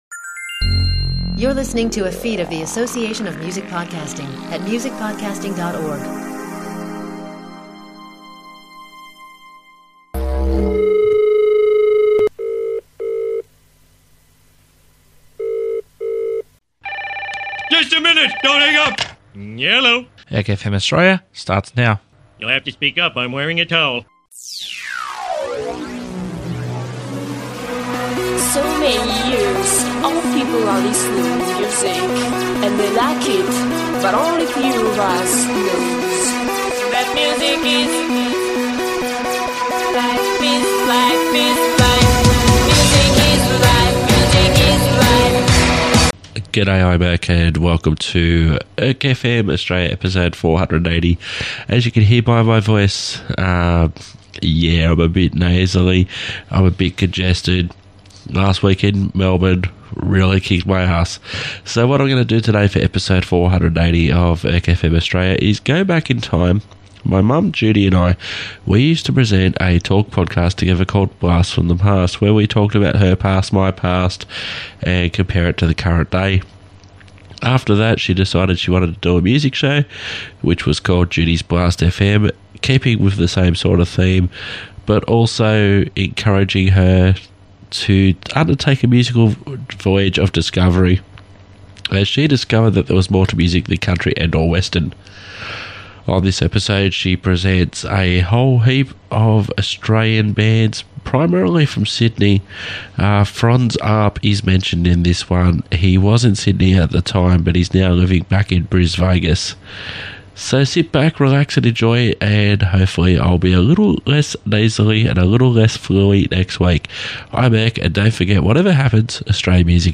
She later decided to do a music show
featuring a series of artists primarily from Sydney